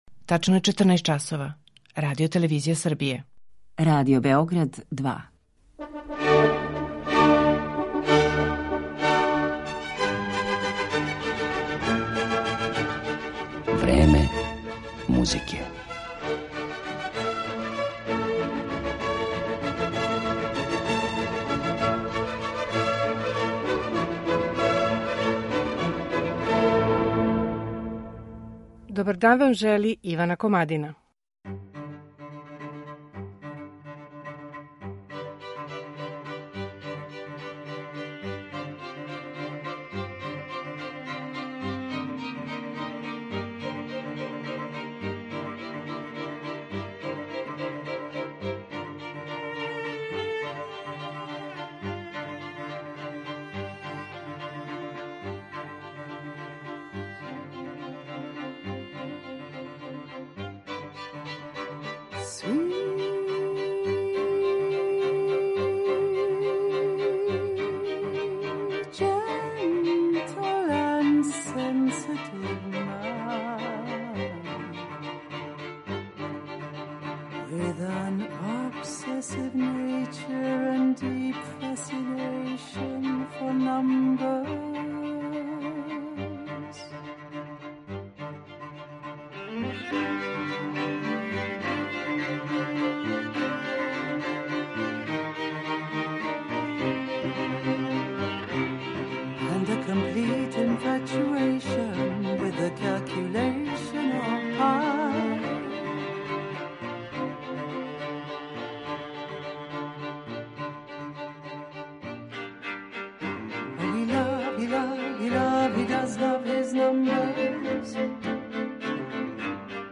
гудачког квартета